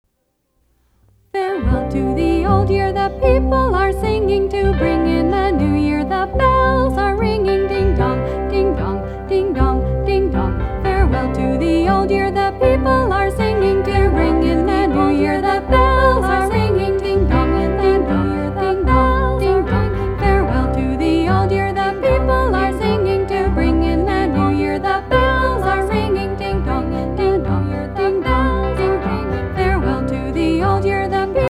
Traditional Lyrics
Sing as a round to celebrate New Year's Day: